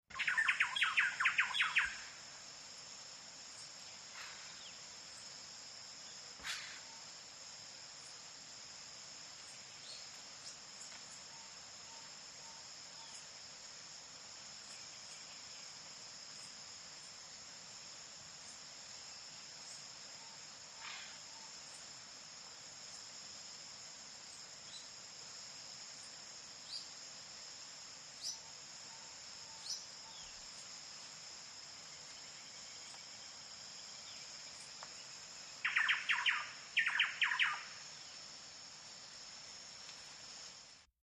Amazon jungle with insects & chirpy exotic bird, Misahualli, Ecuador